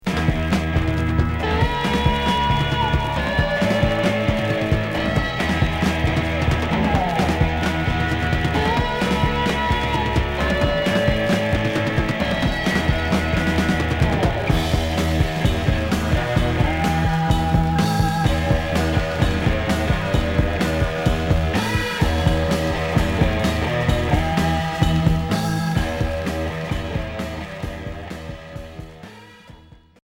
Heavy rock Deuxième 45t retour à l'accueil